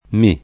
mi] pron. — sempre atono: non mi credi [nom mi kr%di]; unito a un verbo anche nella grafia se enclitico: credimi [